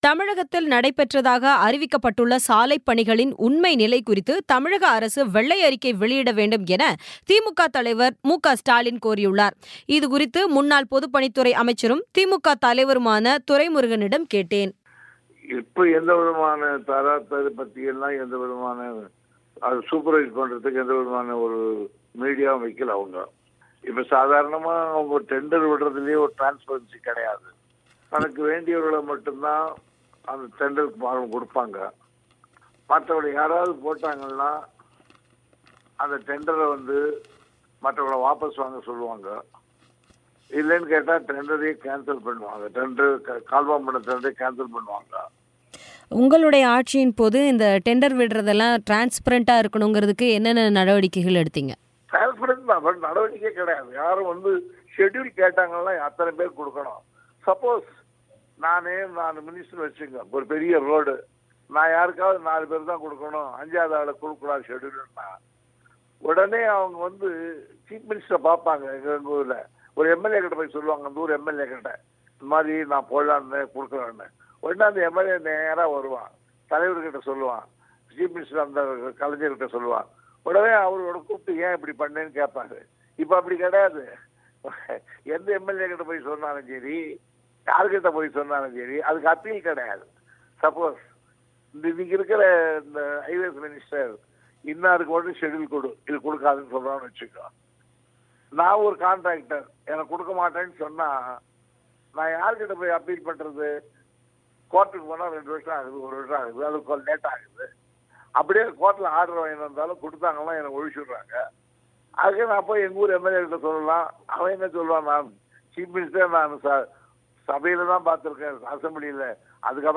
தமிழக சாலைப் பணிகள்: வெள்ளையறிக்கை கோரிய திமுக, முன்னாள் அமைச்சர் துரைமுருகன் பேட்டி